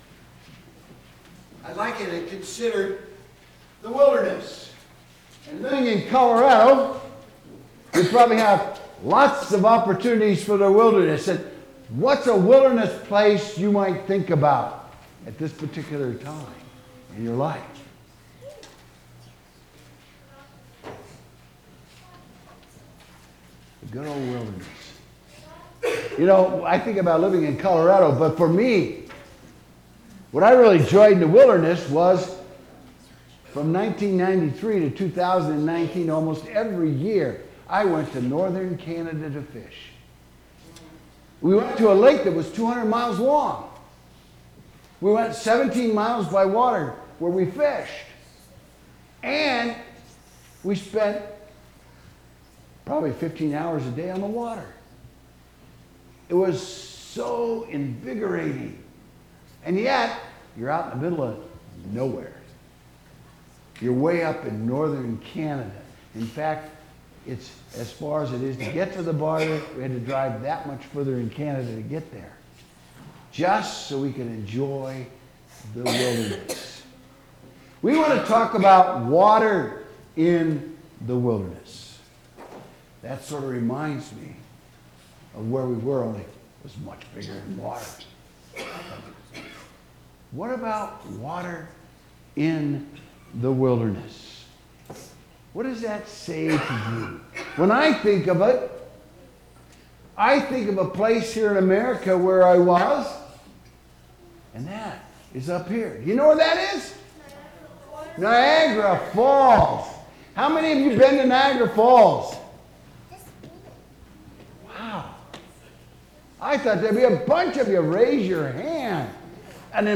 Lent 3 Midweek Service